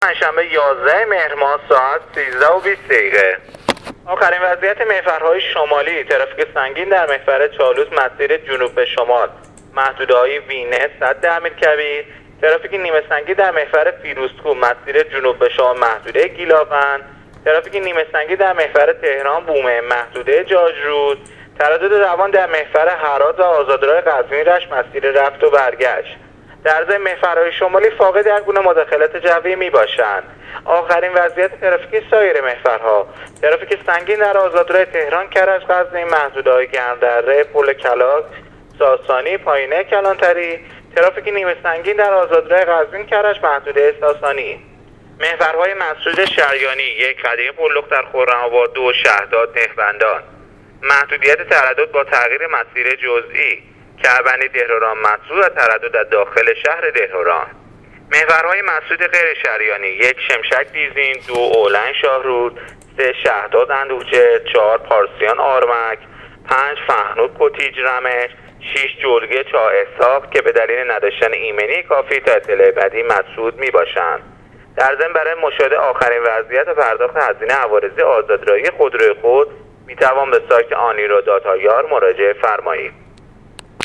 گزارش رادیو اینترنتی پایگاه خبری وزارت راه و شهرسازی از آخرین وضعیت ترافیکی جاده‌های کشور تا ساعت ۱۳:۲۰ یازدهم مهر/ ترافیک نیمه‌سنگین در محور فیروزکوه، تهران-بومهن و قزوین-کرج/ترافیک سنگین در محور چالوس و تهران-کرج-قزوین